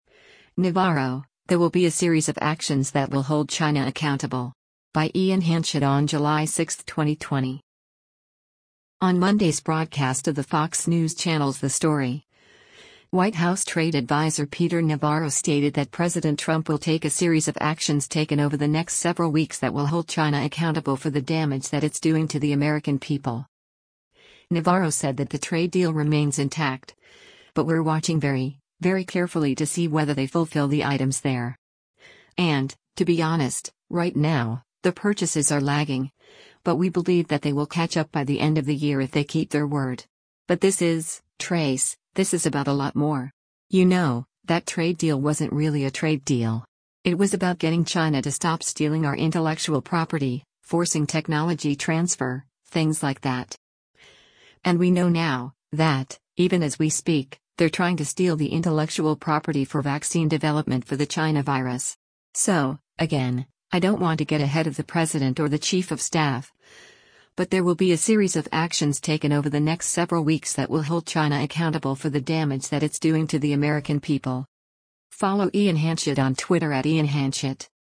On Monday’s broadcast of the Fox News Channel’s “The Story,” White House Trade Adviser Peter Navarro stated that President Trump will take “a series of actions taken over the next several weeks that will hold China accountable for the damage that it’s doing to the American people.”